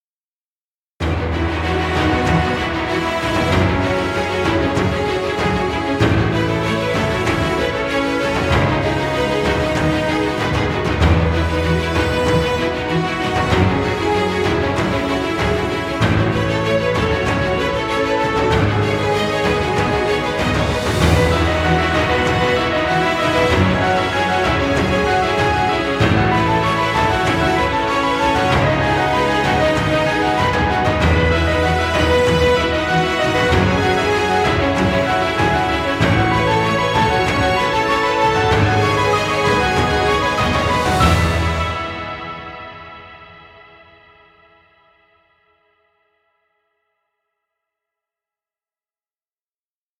Epic music.